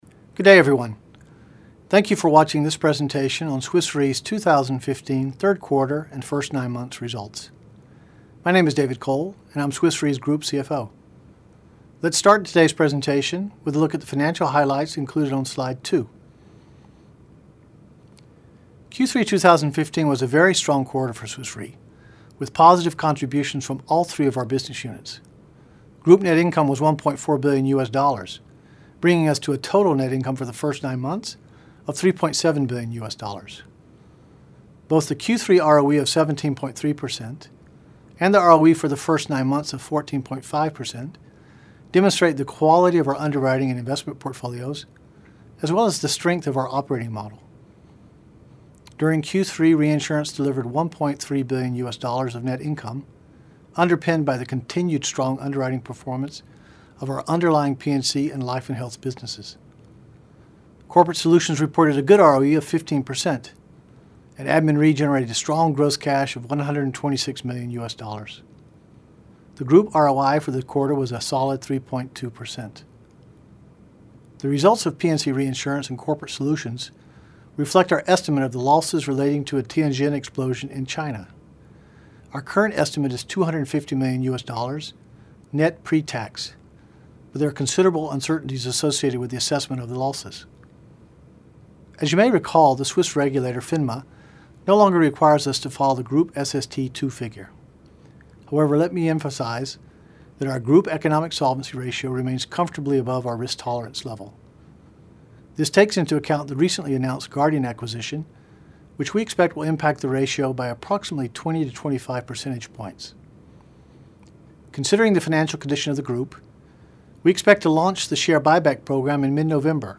Audio of Q3 2015 Results Video Presentation